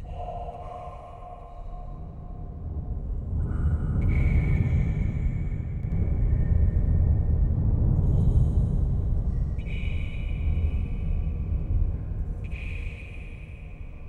hm_iceslide_cave.wav